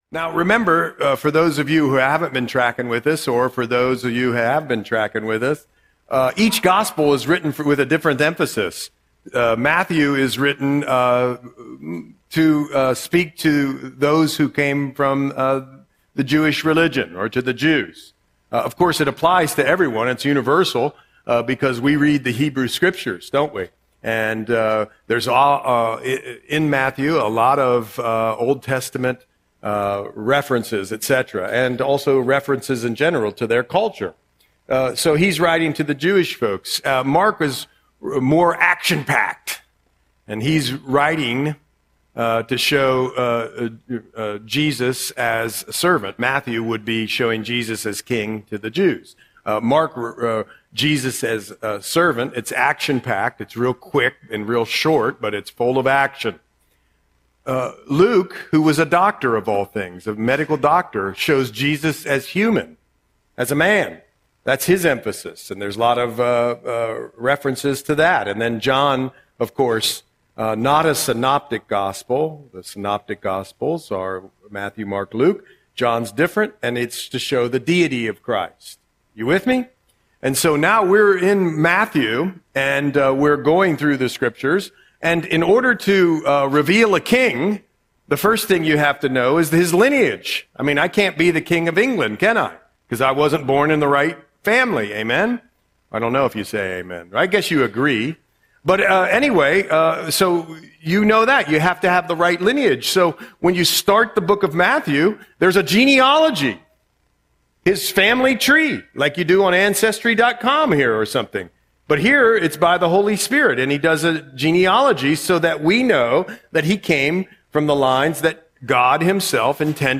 Audio Sermon - January 18, 2026